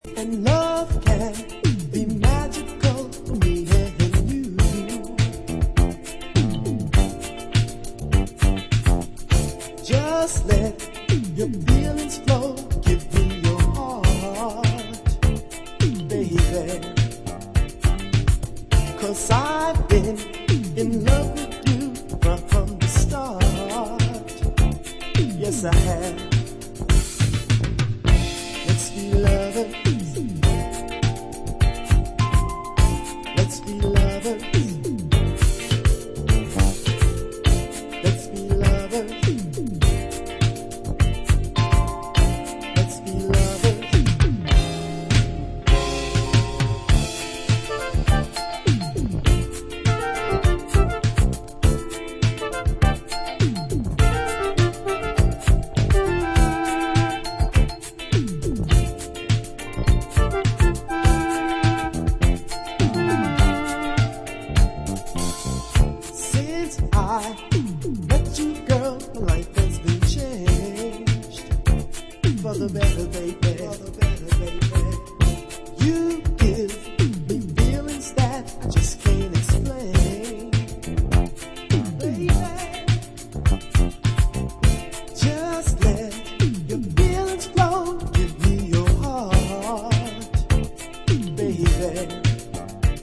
Disco Funk Boogie